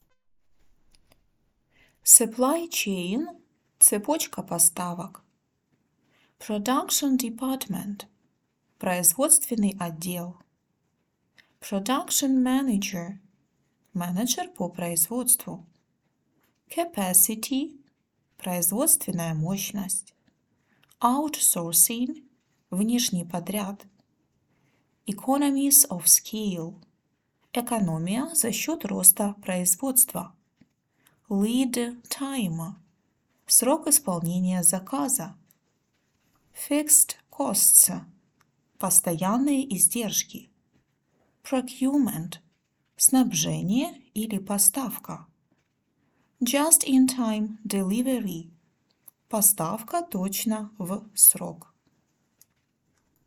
• прослушивайте озвученные английские фразы ежедневно, повторяйте за диктором сначала английский вариант, а затем и русский перевод;